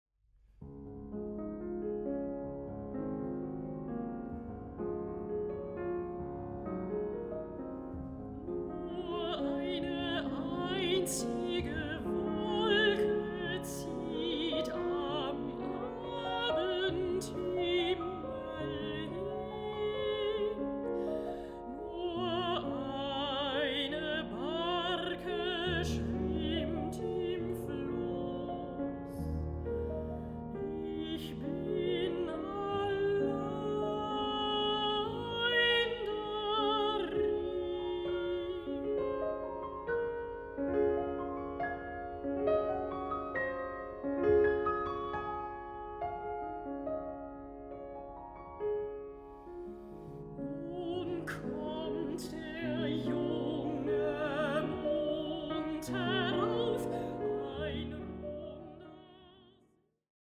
Mezzo-soprano
piano
Recording: Mendelssohn-Saal, Gewandhaus Leipzig, 2025